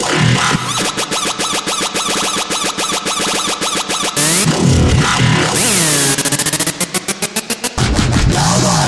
描述：节奏为108，所以它更适合Moombahton/dubstep。慢节奏的音乐人
Tag: 108 bpm Dubstep Loops Bass Loops 1.50 MB wav Key : Unknown